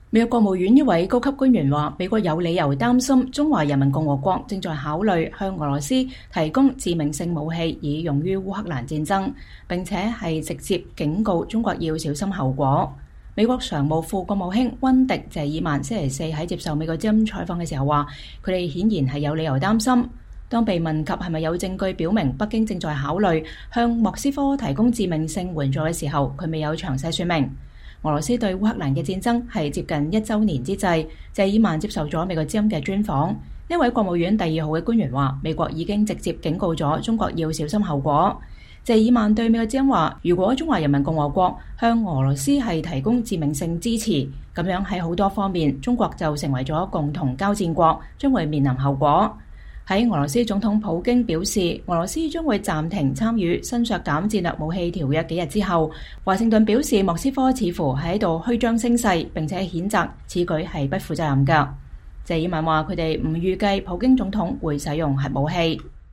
VOA專訪副國務卿謝爾曼：美國有理由擔憂中國正考慮向俄提供致命性武器
俄羅斯對烏克蘭的戰爭接近一週年之際，謝爾曼接受了美國之音專訪。